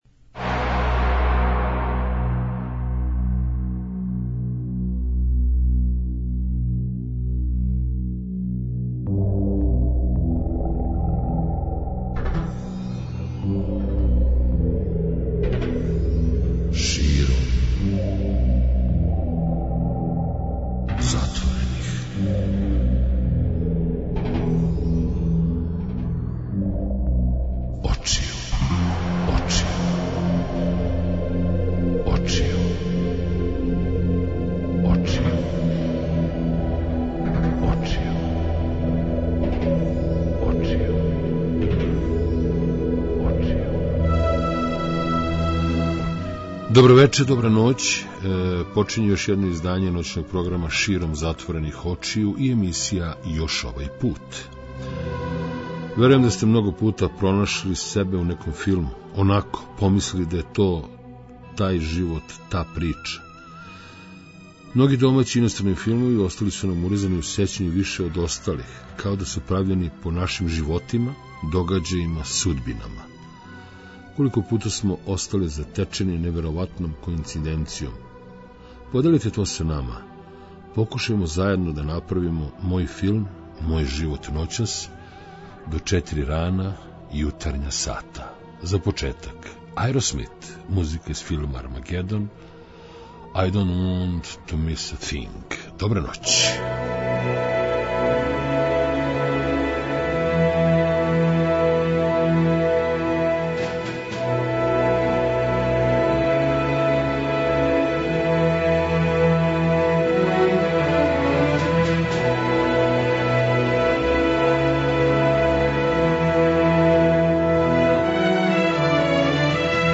преузми : 56.73 MB Широм затворених очију Autor: Београд 202 Ноћни програм Београда 202 [ детаљније ] Све епизоде серијала Београд 202 Састанак наше радијске заједнице We care about disco!!!